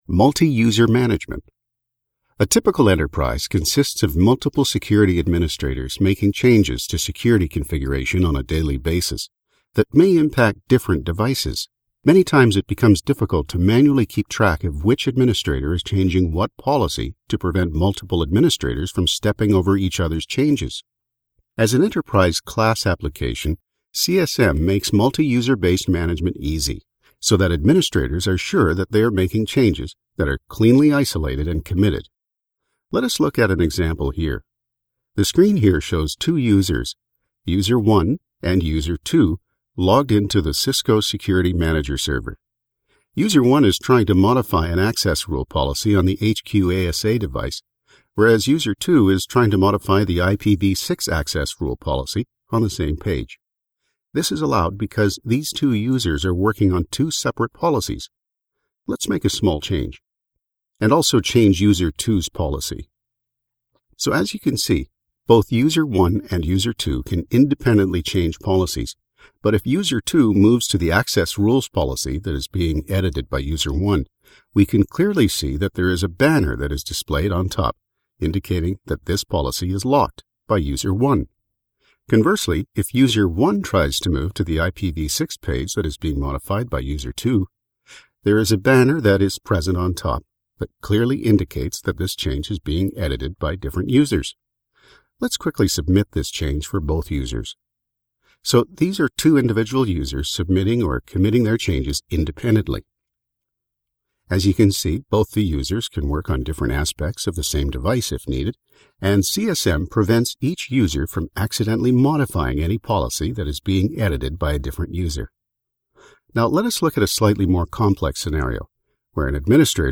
male voice over english north america usa canada confident deep narration announcer middle age confident
Sprechprobe: eLearning (Muttersprache):